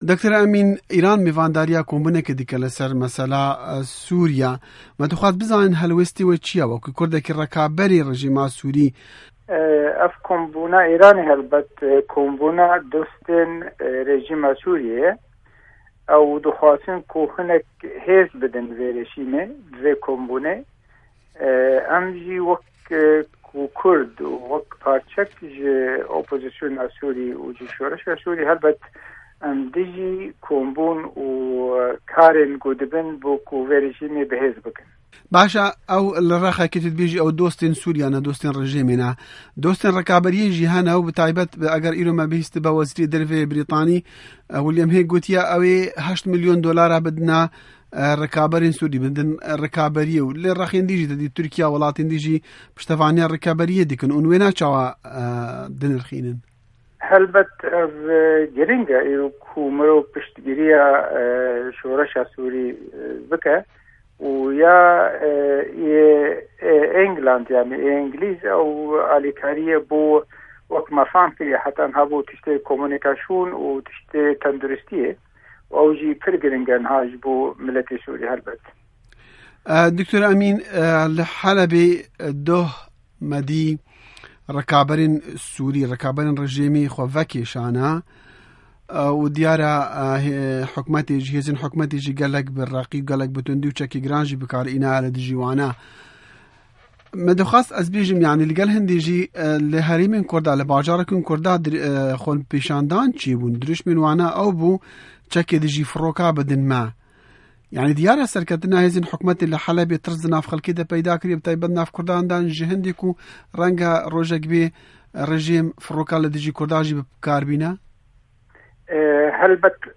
Hevpeyv'în